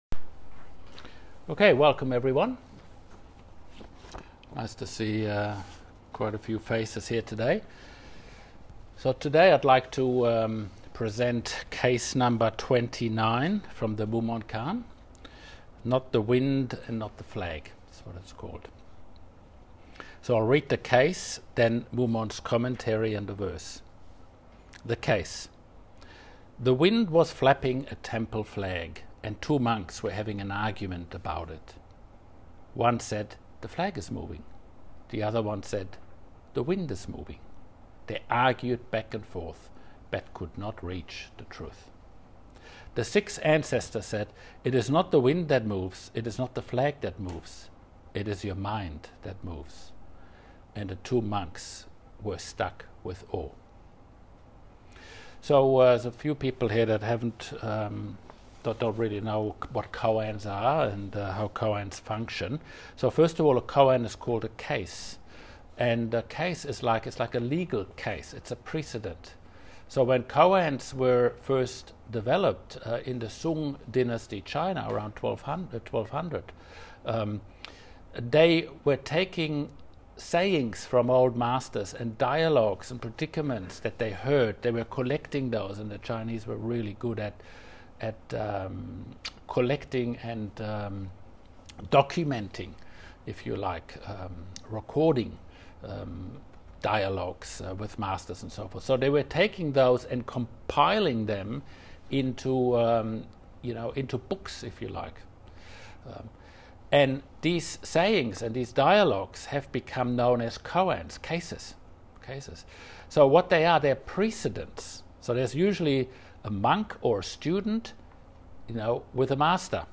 Teisho
at the Pathway Zen Zenkai at Spring Hill, QLD, Australia.